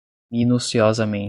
Prononcé comme (IPA)
/mi.nu.siˌɔ.zaˈmẽ.t͡ʃi/